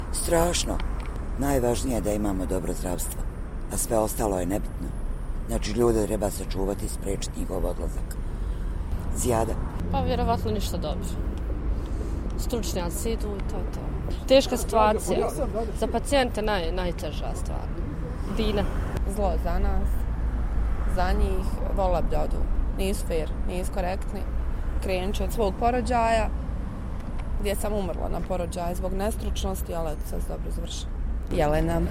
S obzirom da ljekari odlaze, anketirani građani Sarajeva pitaju se ko će ih liječiti.